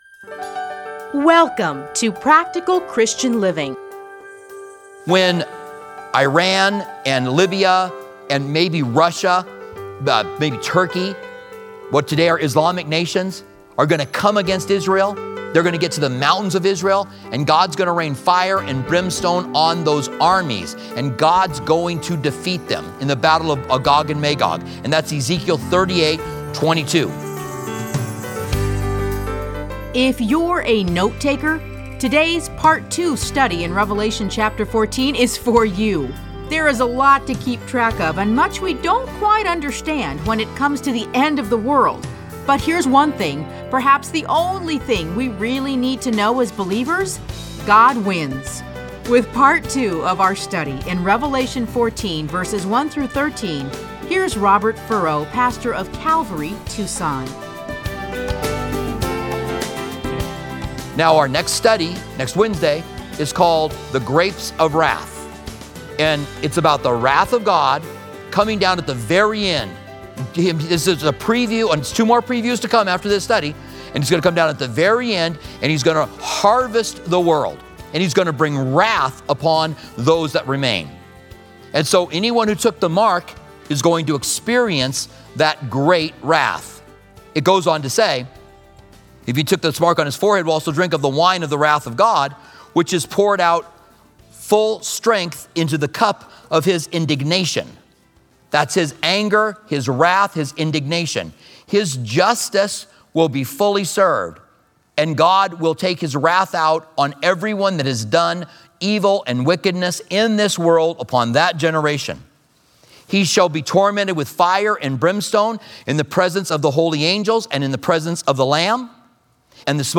Listen to a teaching from Revelation 14:1-13.